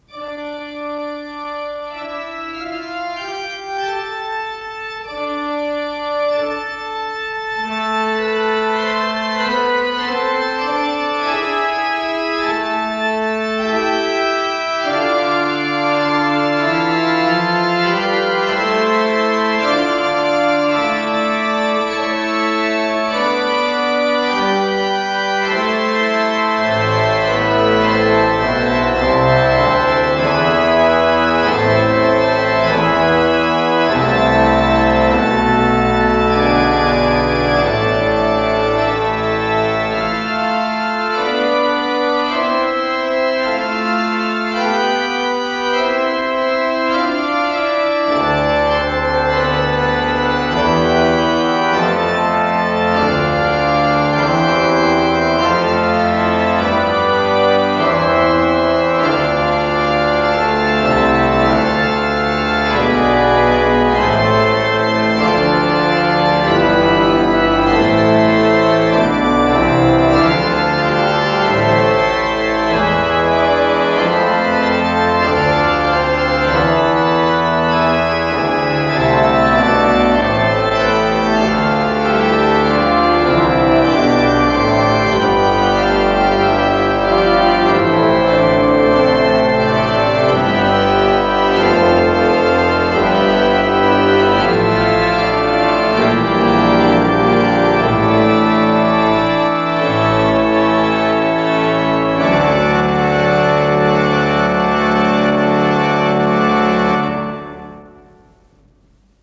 ПодзаголовокРе минор
ИсполнителиГарри Гродберг - орган
ВариантДубль моно